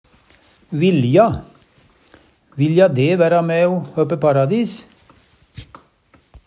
DIALEKTORD PÅ NORMERT NORSK vilja ynskje, ha hug til, Infinitiv Presens Preteritum Perfektum vilja vil /vilja vilde vilja Eksempel på bruk Vilja de væra mæ o høppe paradis?